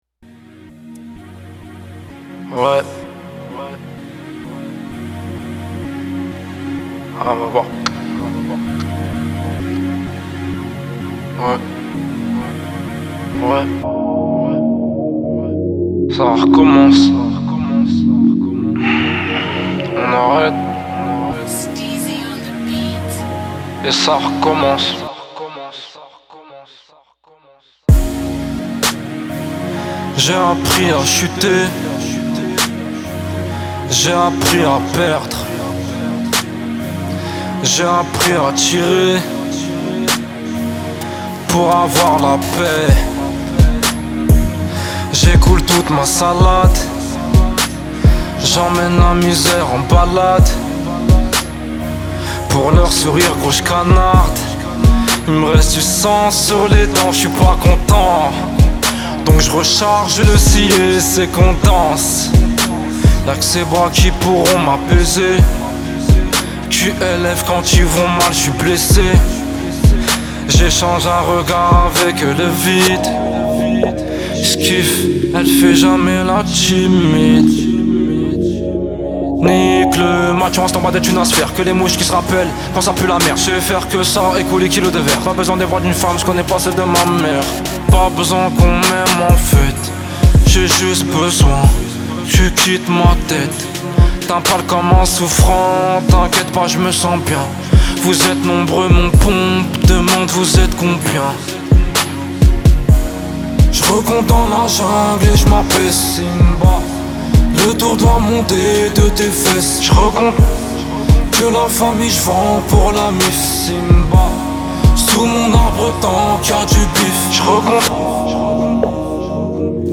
italian trap Télécharger